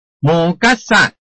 拼音查詢：【饒平腔】gad ~請點選不同聲調拼音聽聽看!(例字漢字部分屬參考性質)